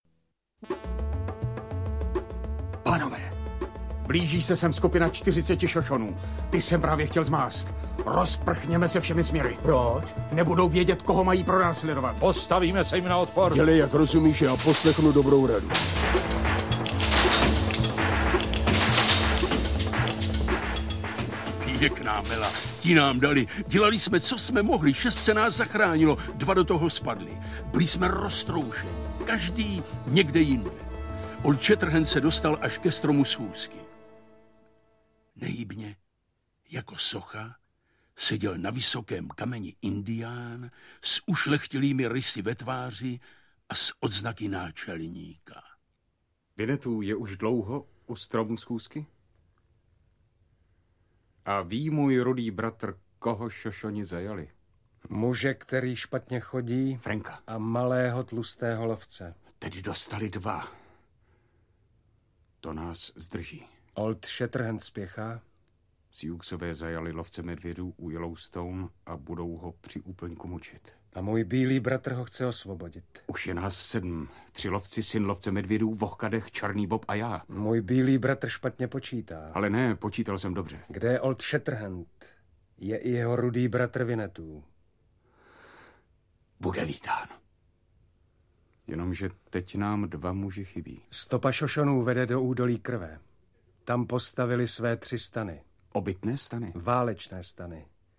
Ukázka z knihy
Tzv. "Velká dramatizace" stejnojmenného románu Karla Maye uzavírá zvukovou trilogii dobrodružství legendárního náčelníka apačů Vinnetoua a jeho bílého bratra Old Shatterhanda, kteří patří již několik generací k nejoblíbenějším klukovským hrdinům. V titulní roli Lukáš Vaculík. Hudbu složil, stejně jako pro obě předcházející mayovky, Ladislav Štaidl, který ji také se svým orchestrem nahrál.